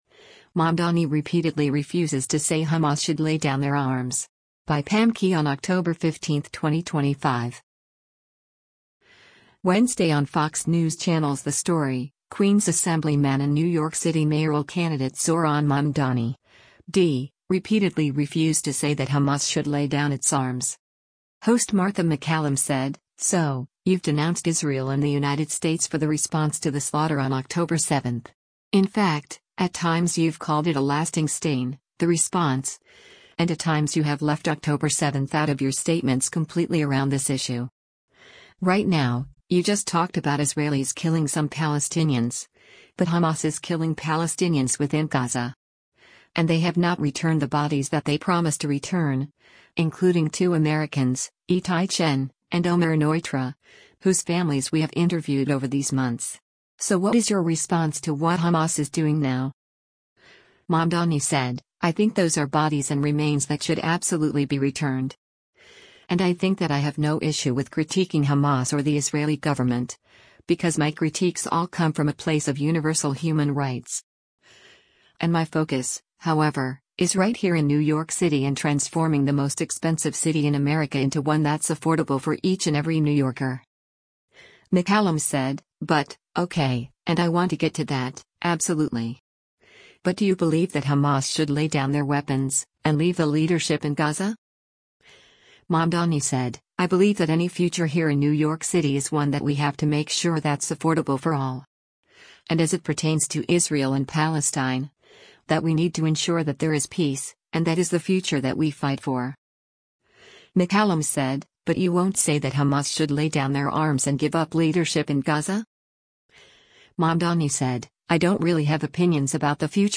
Wednesday on Fox News Channel’s ‘The Story,” Queens Assemblyman and New York City mayoral candidate Zohran Mamdani (D) repeatedly refused to say that Hamas should lay down its arms.